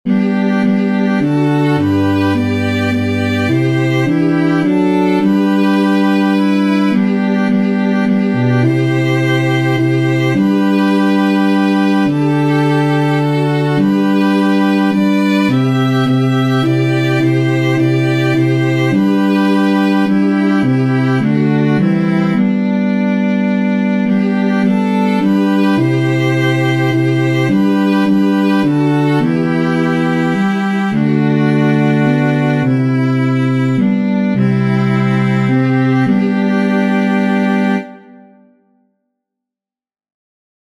• Catégorie : Antienne d'ouverture